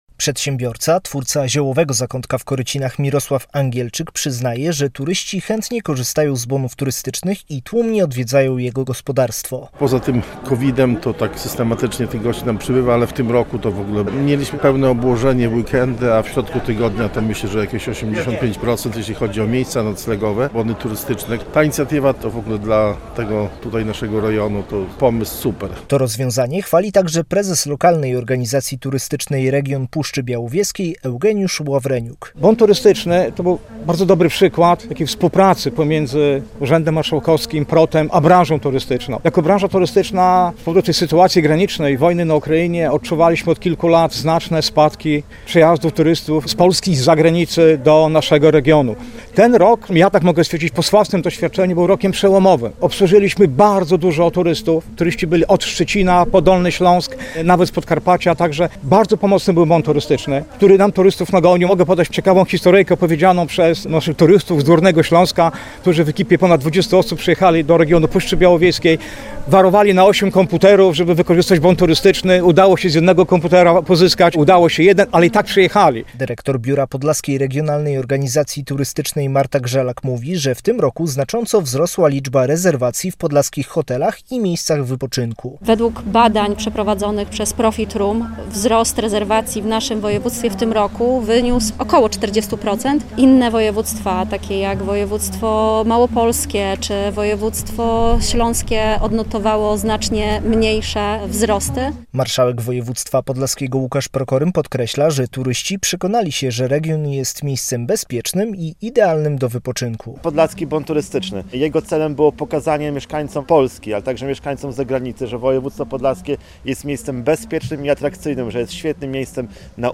Radio Białystok | Wiadomości | Wiadomości - Podlaski Bon Turystyczny ma być kontynuowany w 2026 r.